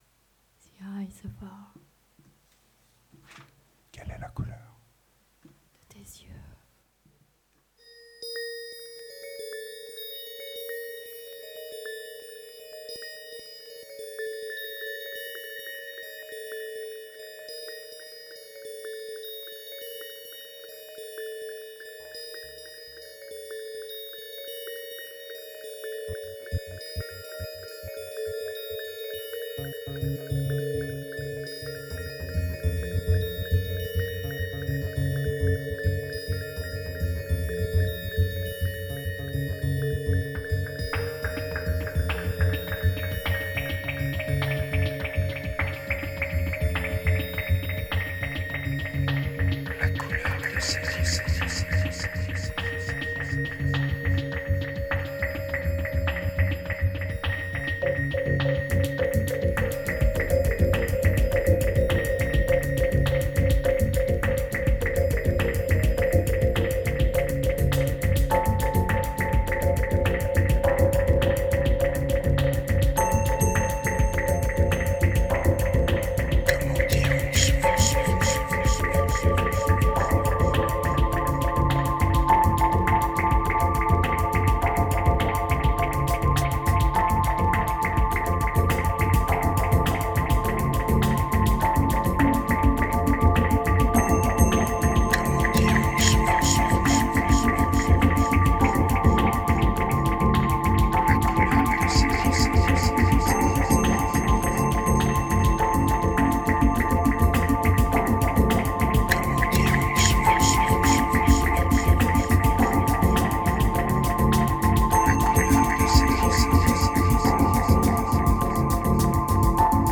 2070📈 - 11%🤔 - 103BPM🔊 - 2012-09-30📅 - -60🌟